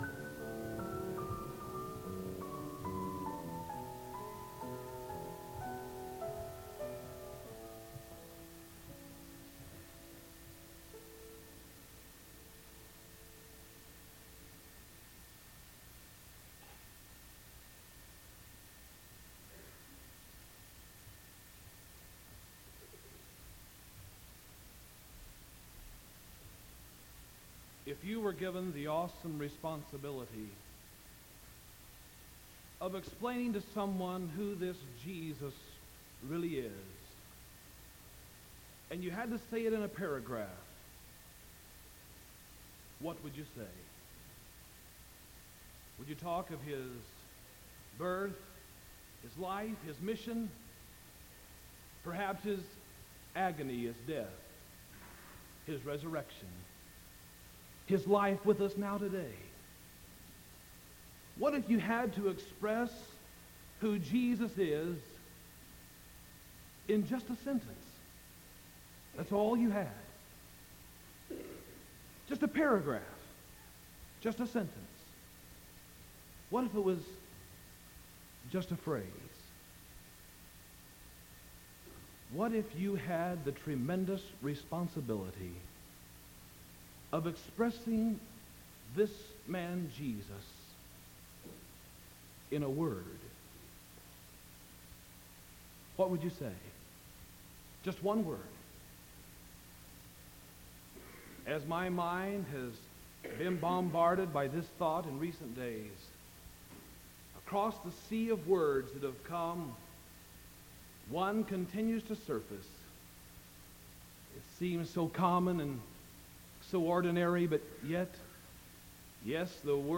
Sermon December 21st 1975 PM